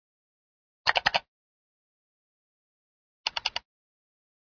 doubleclick.wav